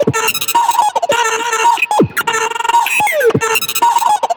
这款采样包包含 62 个极具感染力的人声采样，是您为音乐注入原始能量的理想之选。